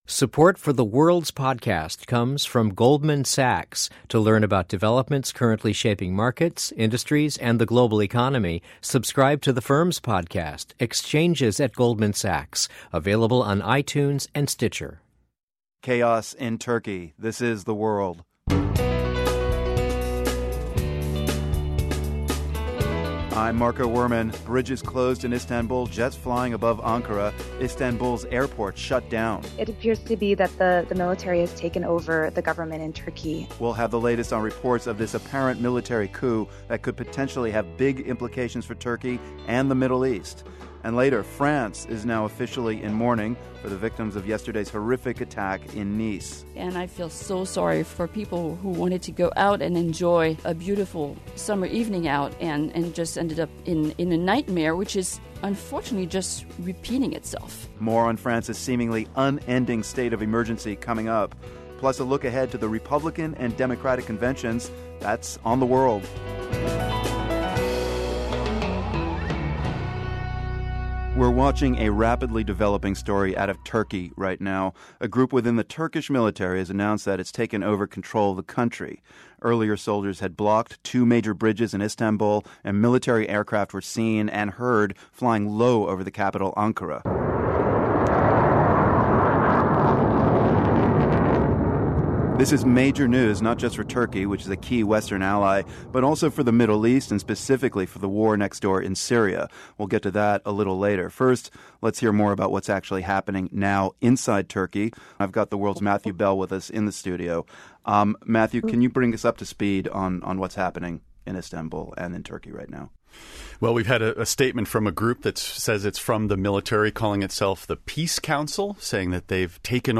(This episode was recorded at 6 p.m. EST.)